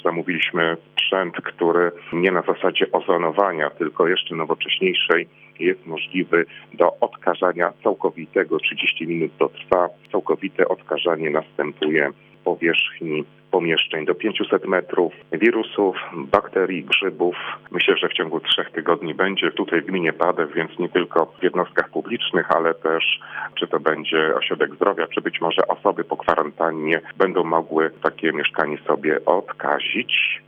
Wójt gminy Padew Narodowa Robert Pluta apeluje do swoich mieszkańców aby stosowali się do zaleceń, które mają na celu ograniczanie rozprzestrzeniania się koronawirusa. Również w tym celu gmina w najbliższych dniach jak zaznacza wójt, będzie korzystała z nowo zakupionego urządzenia dezynfekującego i oczyszczającego pomieszczenia z wszelkich bakterii.